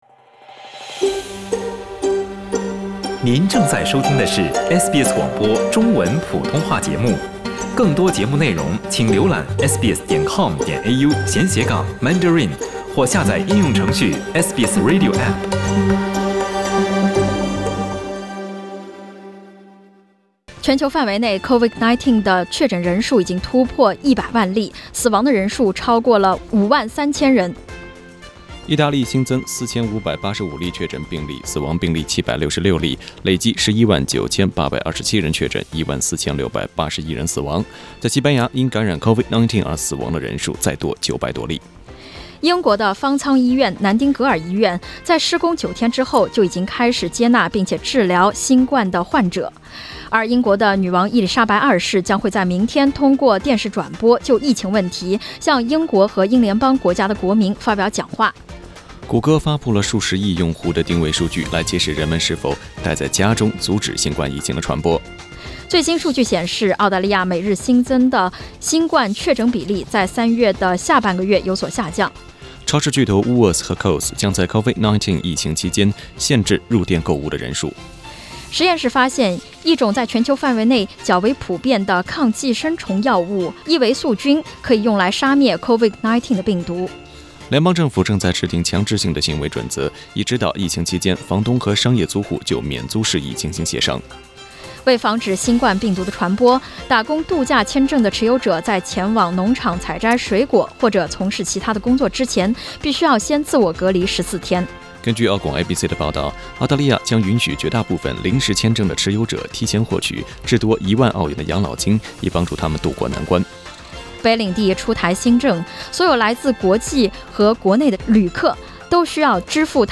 SBS早新闻（4月4日）